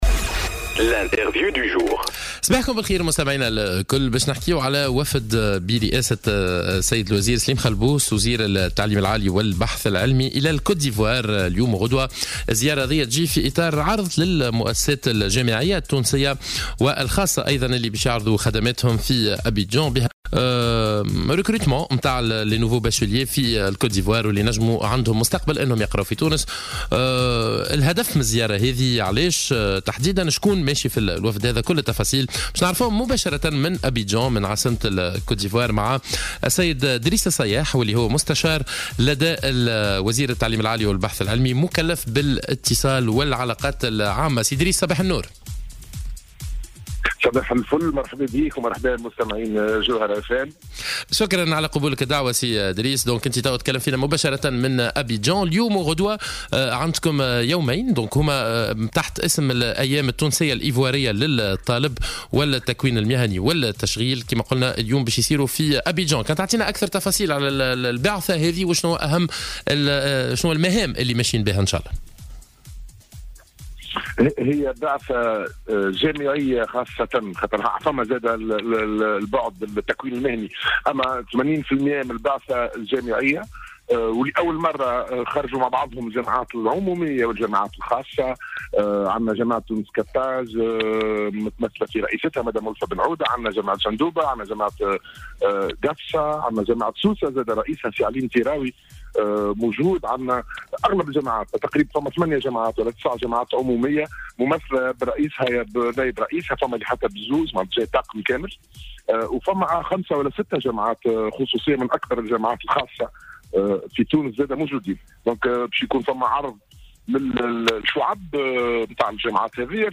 وأضاف في اتصال هاتفي من أبيدجان مع "الجوهرة اف أم" في برنامج "صباح الورد"، أن وفدا من وزارة التعليم العالي يؤدي حاليا زيارة رسمية إلى كوت ديفوار للترويج للجامعات التونسية العمومية منها والخاصة.